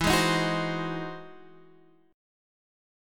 E Augmented Major 9th